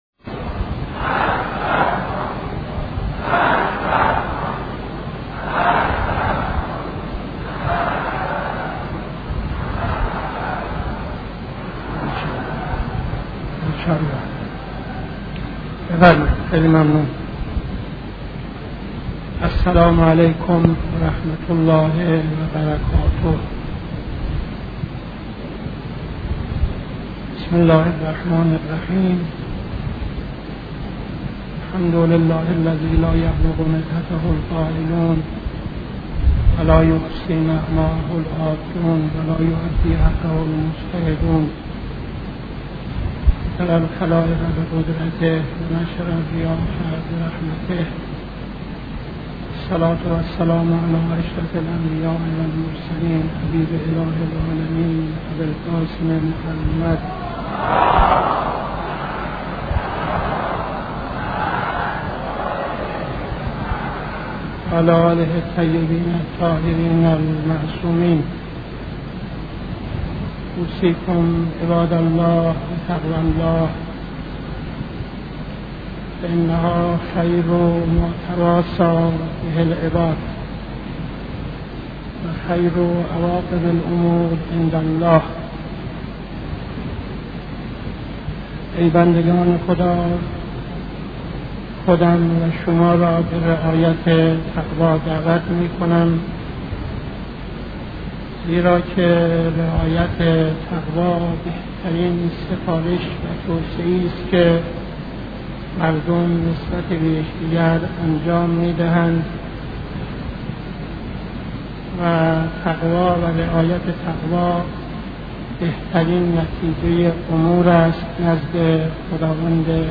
خطبه اول نماز جمعه 06-09-71